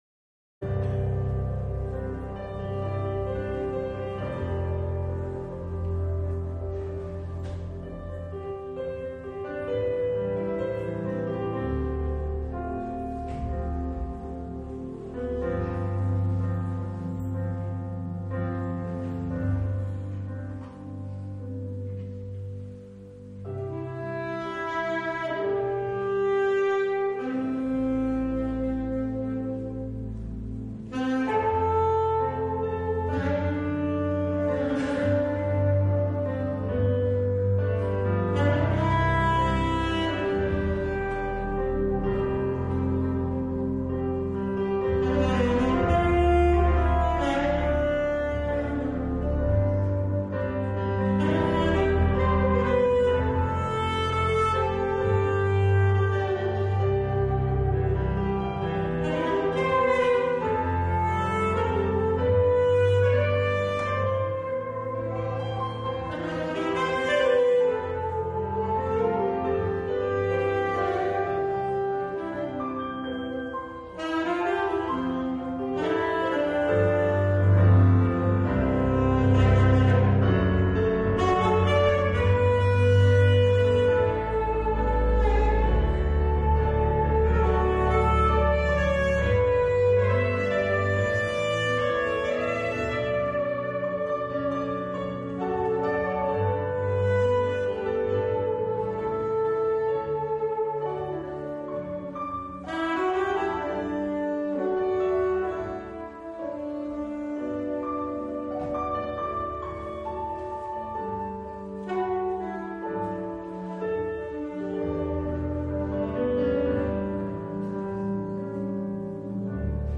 Predigt zum Reformationsfest 2022 über EG 355,1 - Kirchgemeinde Pölzig
Predigt-zum-Reformationsfest-zu-EG-3551.mp3